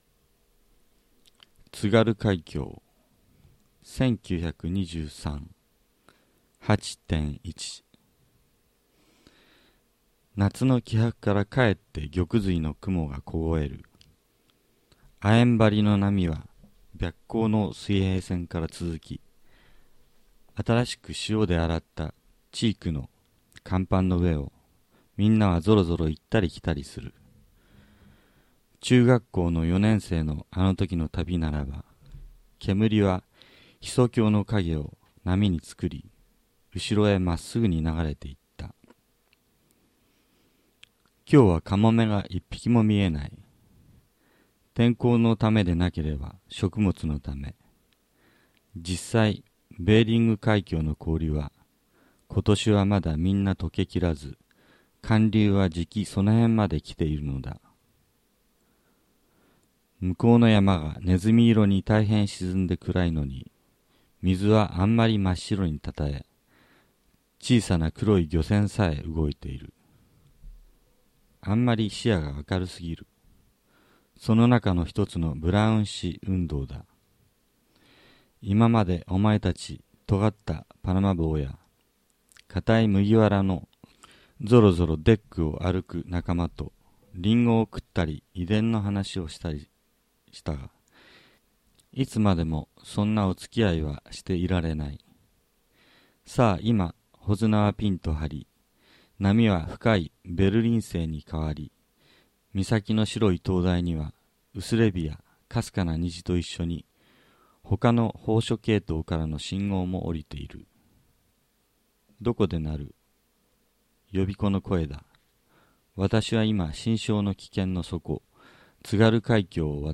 宮沢賢治全作品朗読